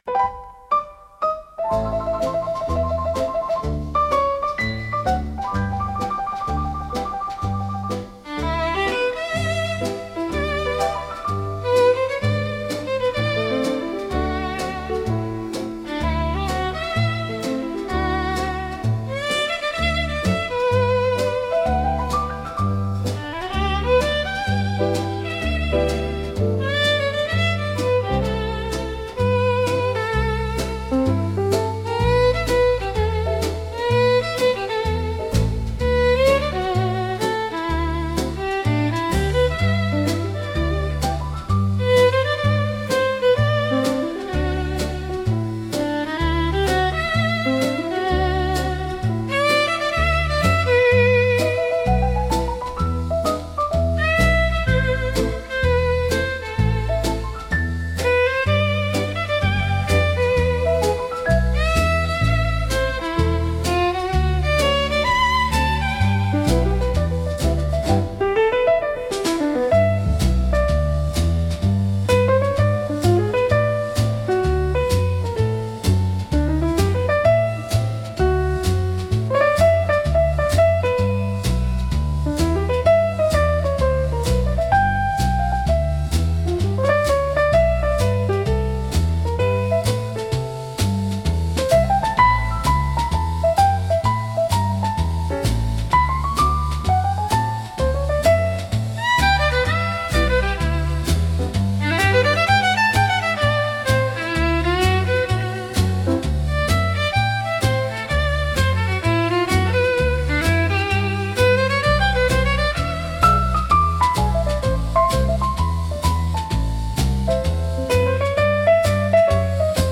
música 2 arranjo: IA) instrumental 10